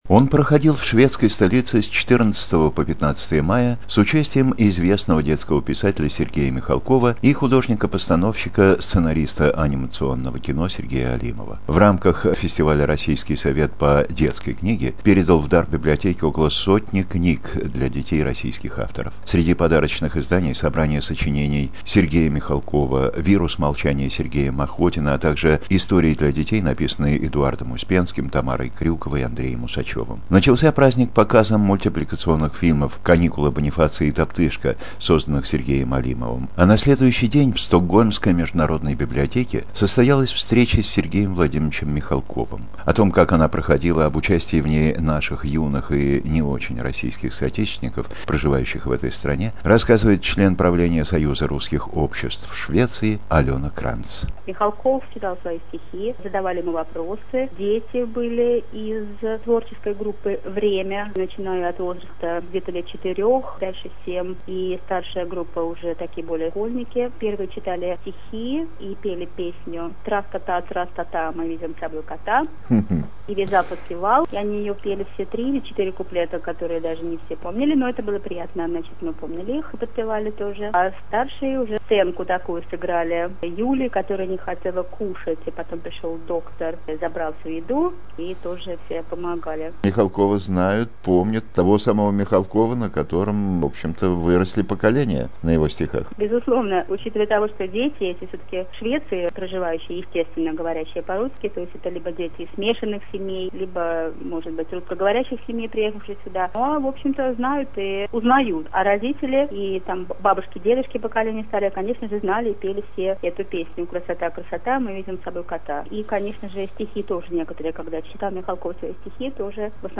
Интервью журналиста
Короткий репортаж и